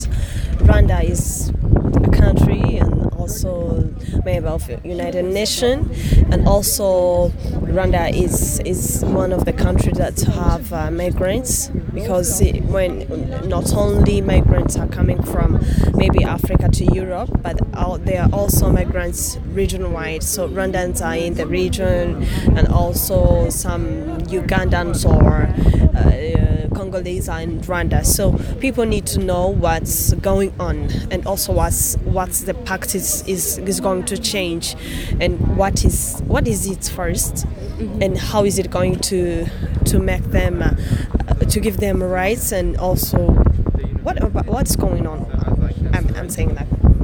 The whole world met at Marrakech, Morocco, during the two days of the Global Compact for Migration. IPS met six people to ask what led them to come to this international event.